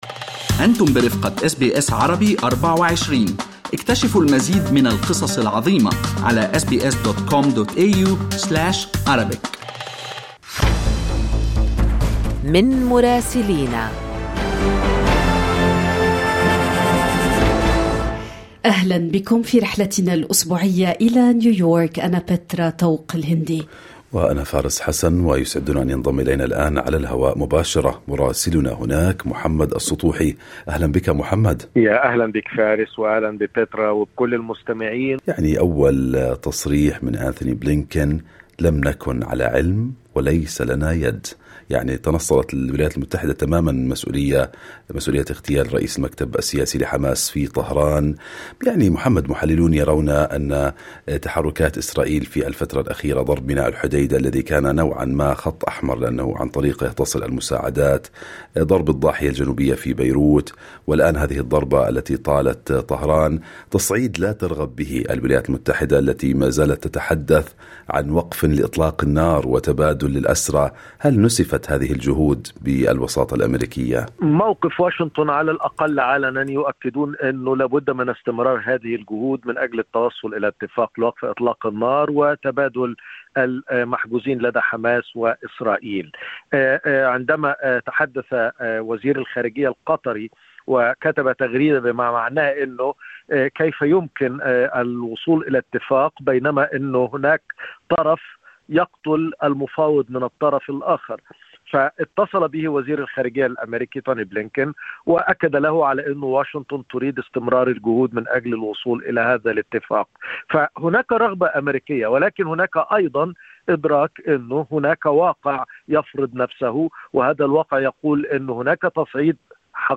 من مراسلينا: أخبار الولايات المتحدة الأمريكية في أسبوع 1/8/2024